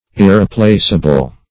irreplaceable.mp3